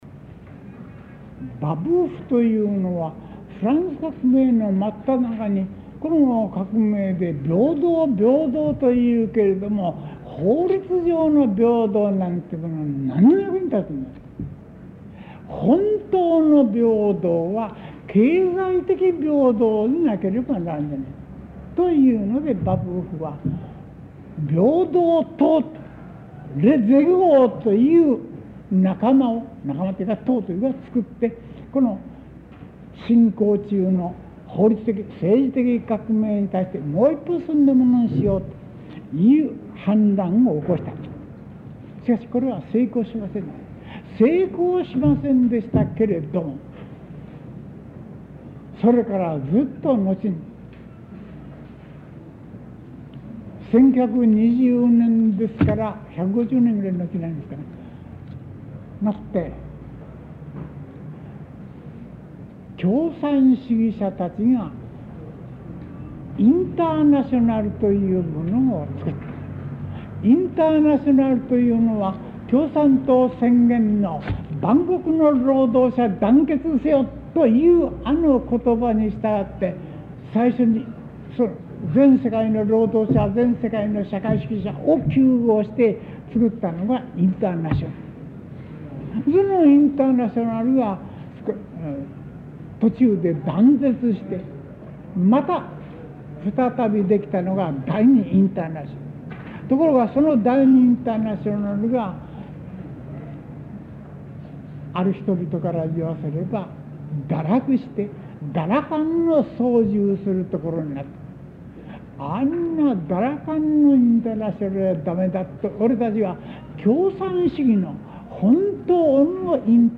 講義録音テープ 7 | NDLサーチ | 国立国会図書館
テープ種別: Sony-SuperA TypeA7-120